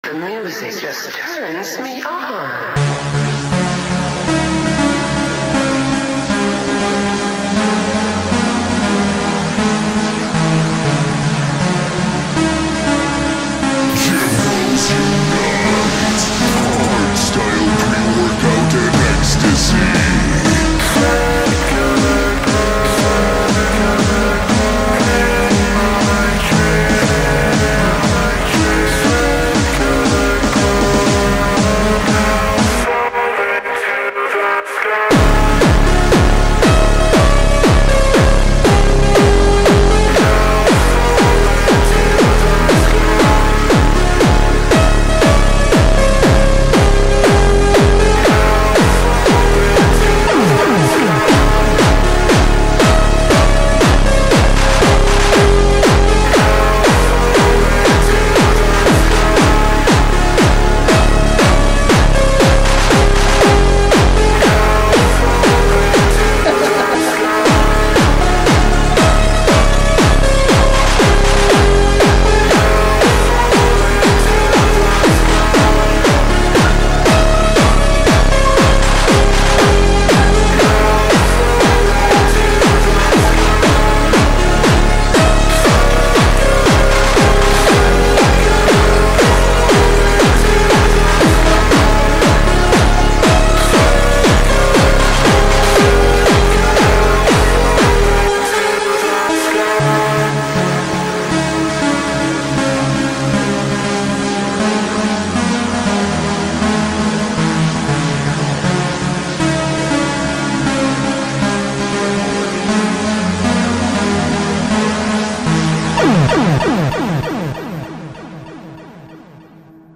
فانک
باشگاهی